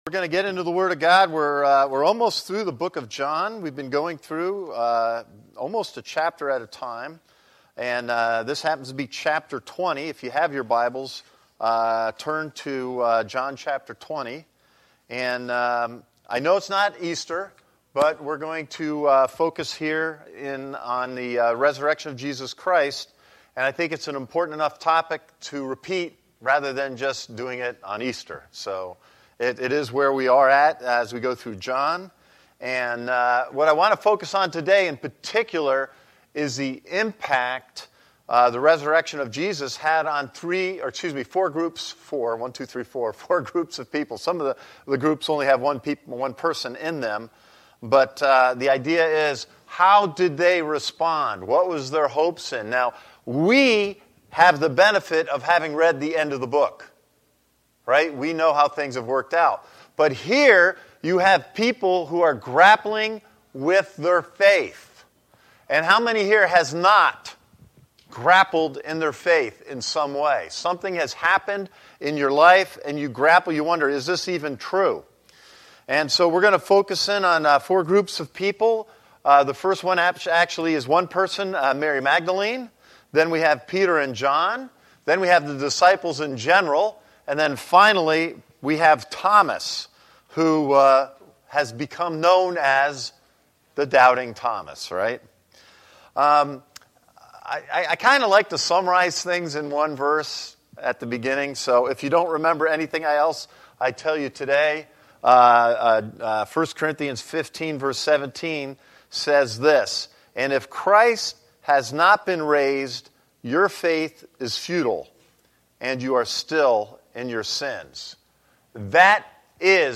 A message from the series "Sunday Service."
This is the main Sunday Service for Christ Connection Church